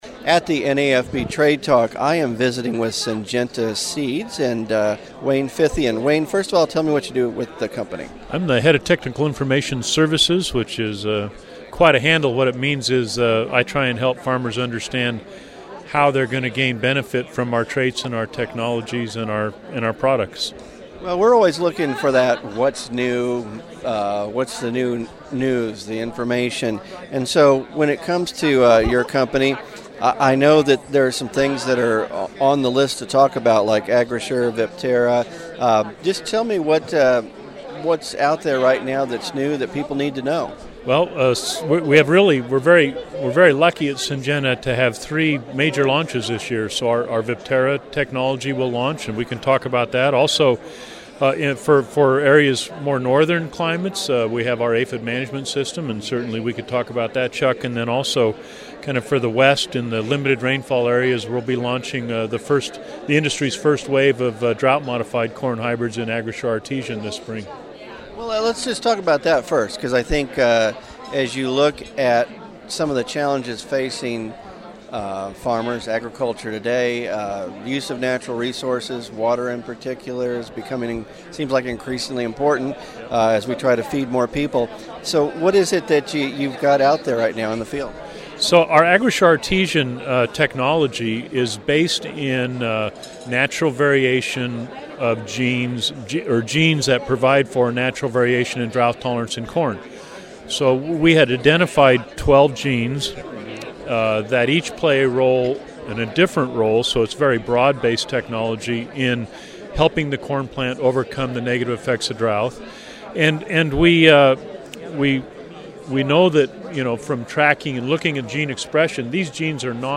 Syngenta Interview